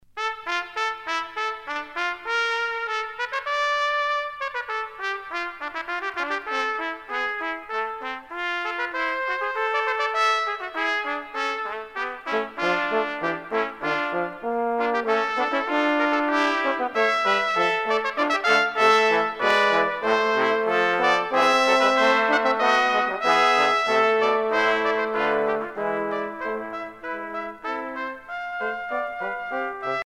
Quintette de cuivres